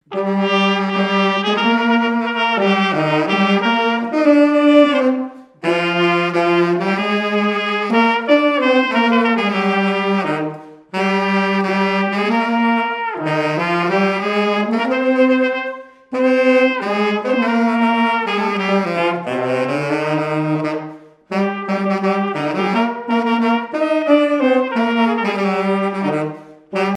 Champagné-les-Marais
circonstance : fiançaille, noce
Pièce musicale inédite